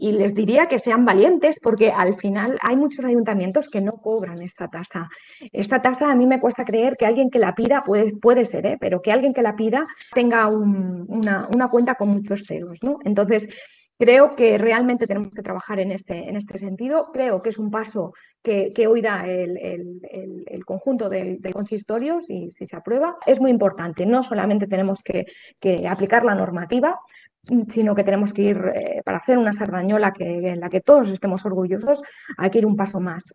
La portaveu del grup taronja,
Sonia Rodríguez, es mostrava satisfeta perquè pensa que “es rectifica una injustícia, i demanaríem valentia al govern recordant-li que alguns Ajuntaments no cobren aquesta taxa”.